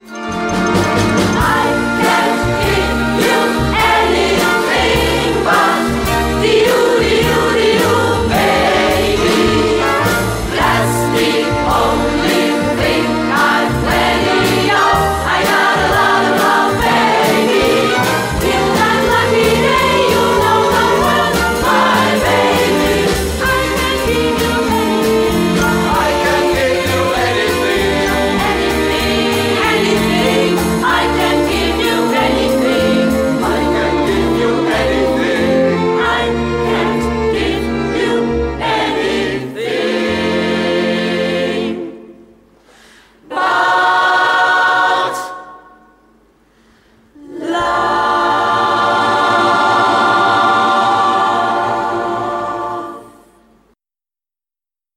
Orchester
SMATB & Solo M